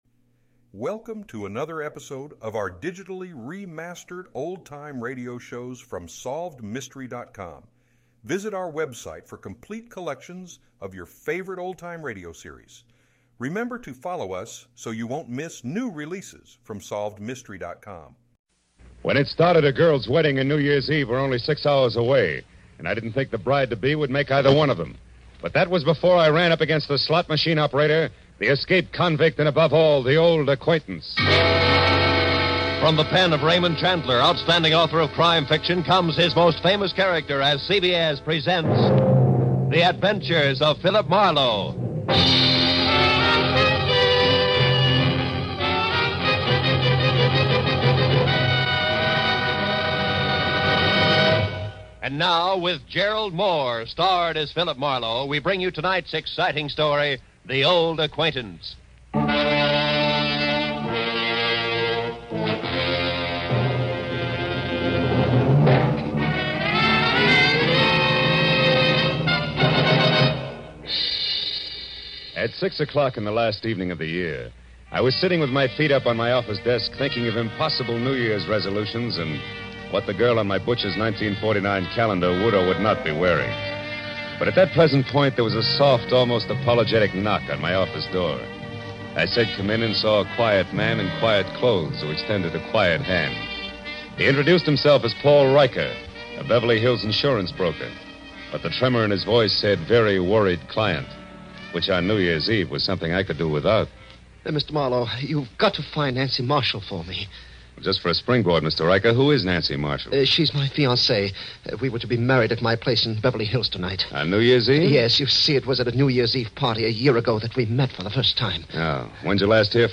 Gerald Mohr starred as Marlowe, and the show was known for its gritty and realistic portrayal of crime and the criminal underworld.
In addition to Mohr's excellent portrayal of Marlowe, the show also featured a talented supporting cast of actors and actresses, including Howard McNear and Parley Baer.